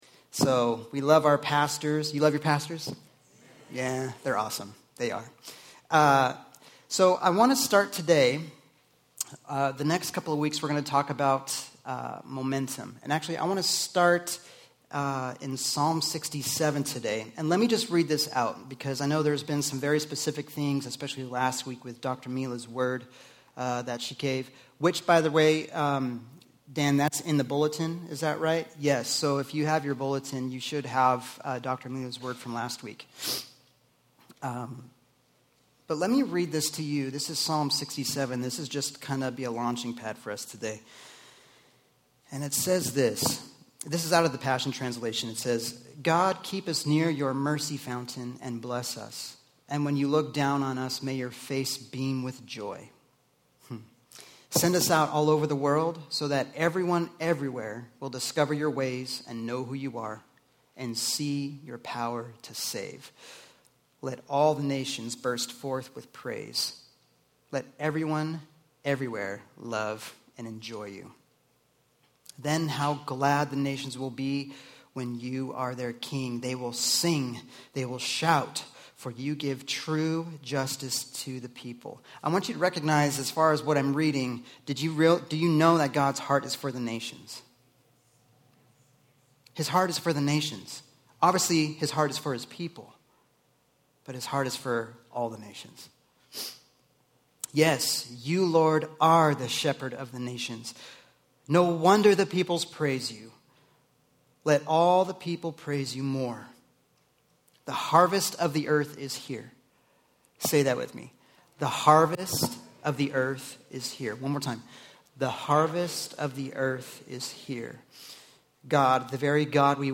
Sermon Series: MOMENTUM